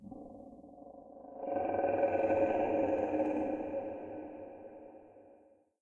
Ambient10.ogg